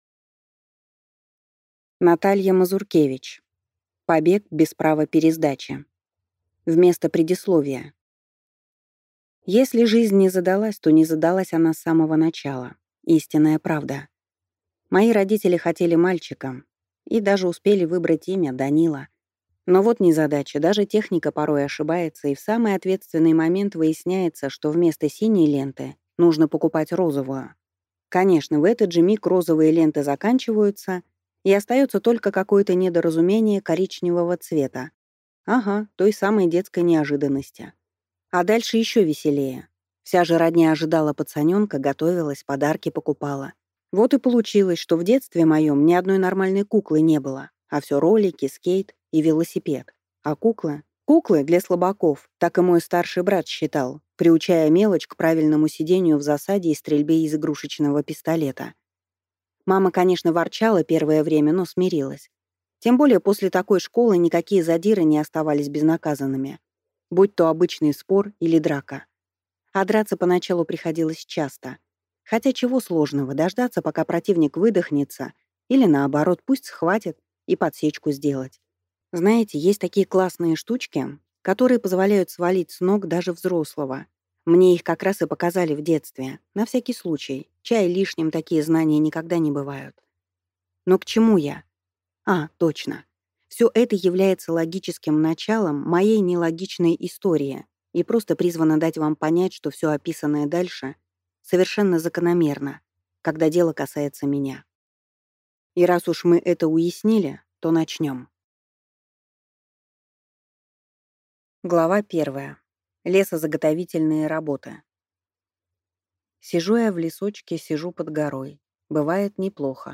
Аудиокнига Побег без права пересдачи | Библиотека аудиокниг